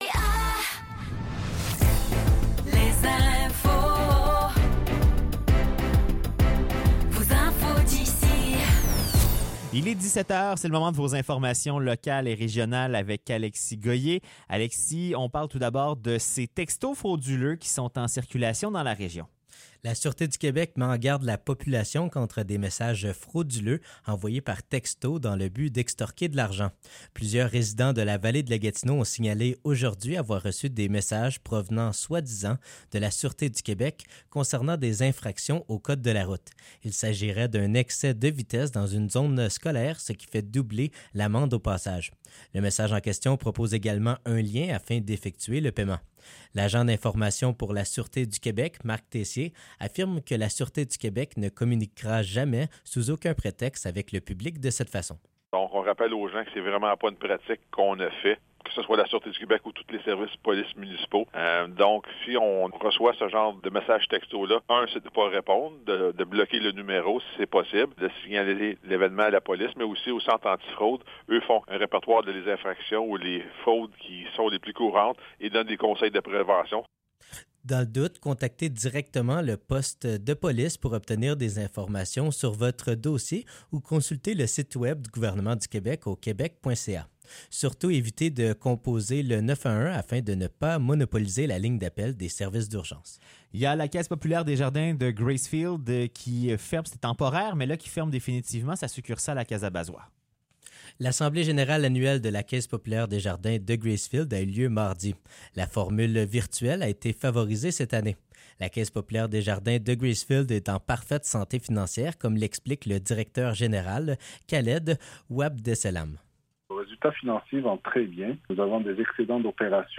Nouvelles locales - 4 avril 2024 - 17 h